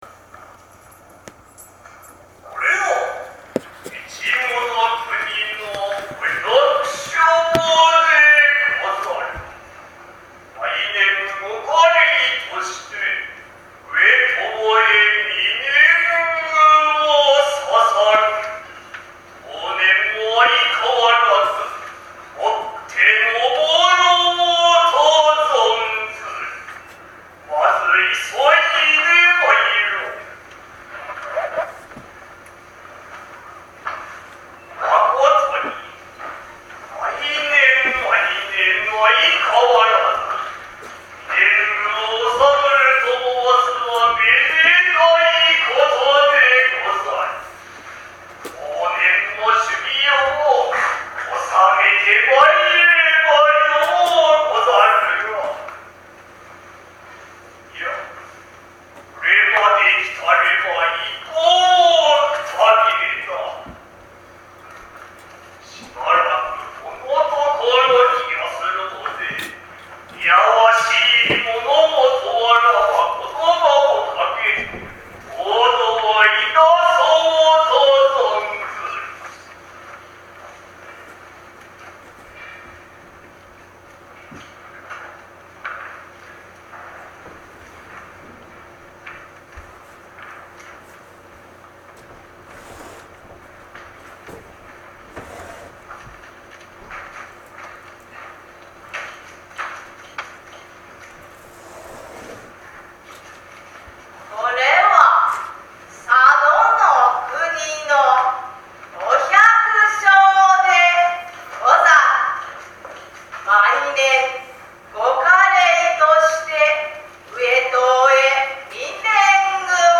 会場は小牧駅から10分程の小牧市民会館である。
狂言の演目は佐渡狐であった。